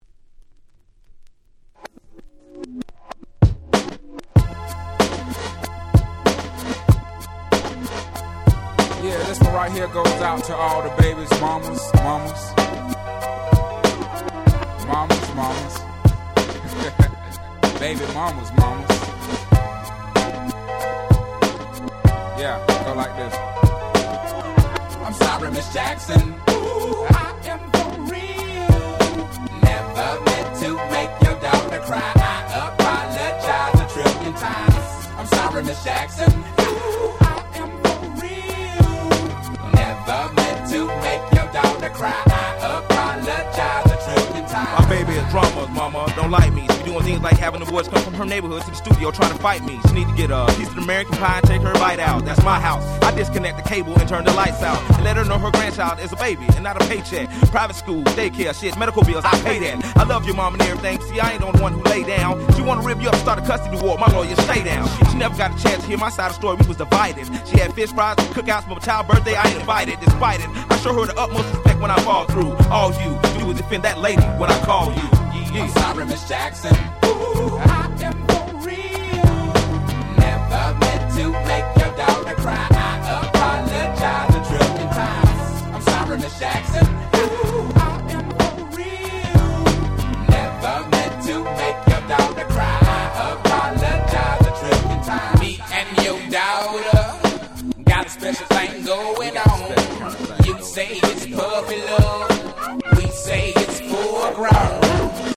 01' Monster Hit Hip Hop !!